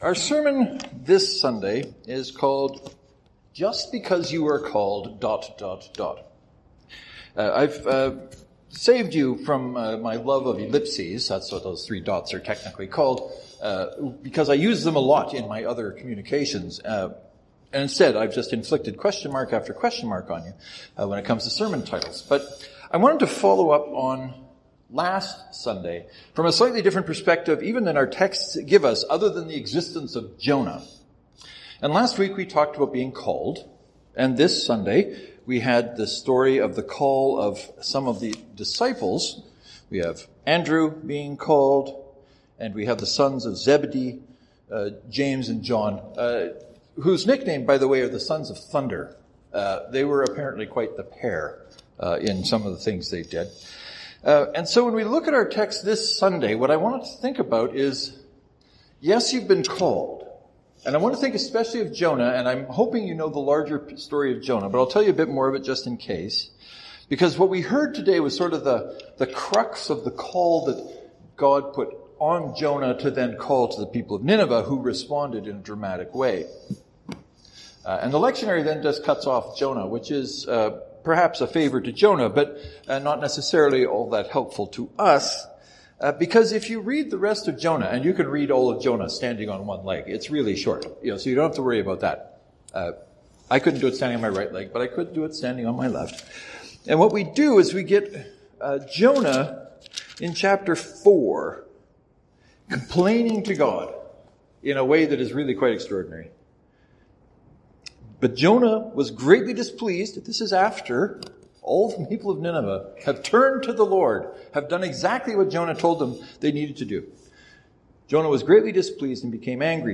Give the sermon a listen and know I am aware of the difference between Michael Jackson and Michael Jordan . . . no matter what it might sound like.
St. Mark’s Presbyterian (to download, right-click and select “Save Link As .